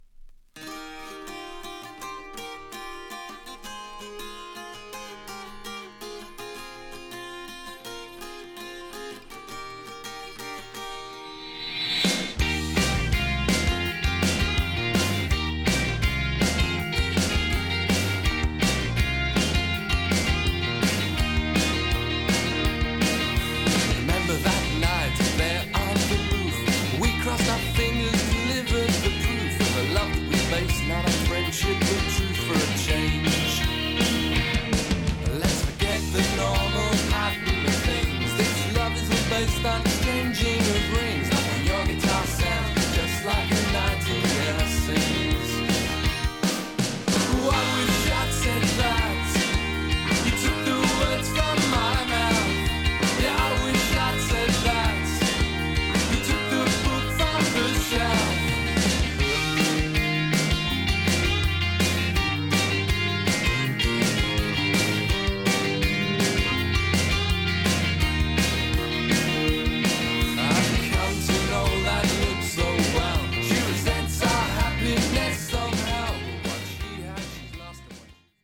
初々しいメロディが炸裂する彼ら名曲中の名曲♪